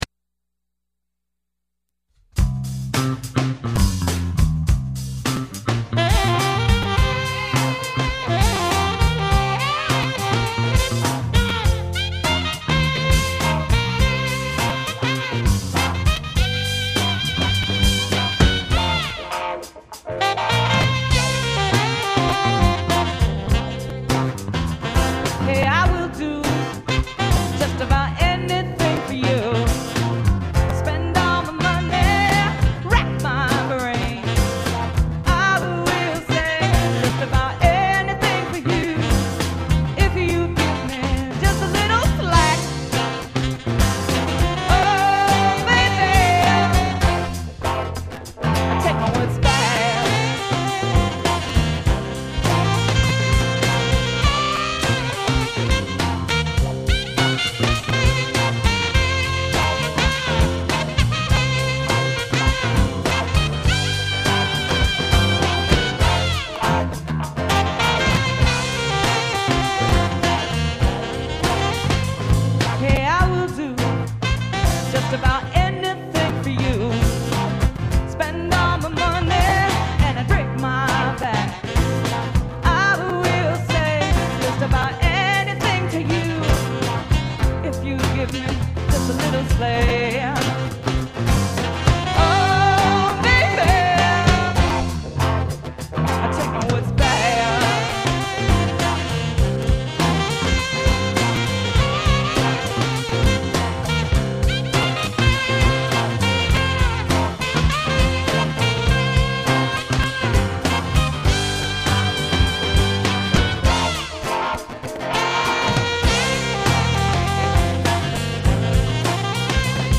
fast and funky